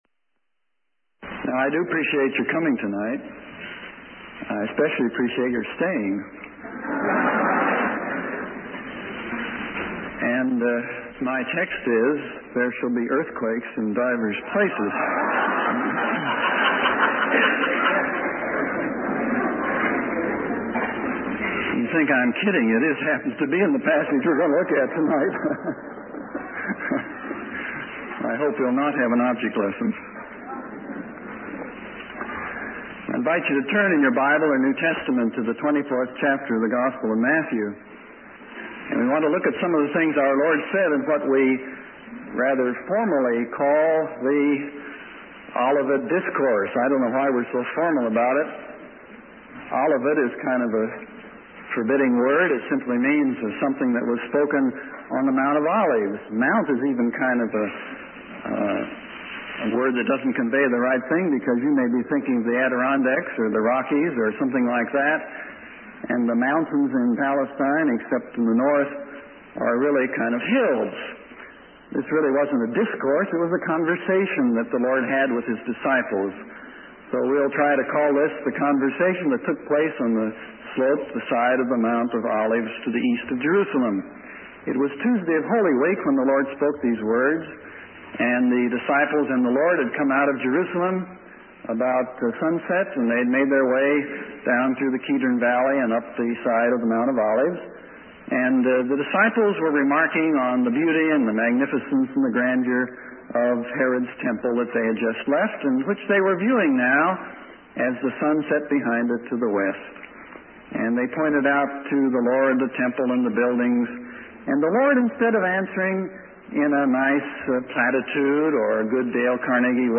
In this sermon, the preacher discusses the concept of anarchy that will occur during the tribulation period. He describes the various aspects of anarchy that will take place, such as the burning of grass and destruction of trees, leading to a disruption in the cycle of rainfall and food production.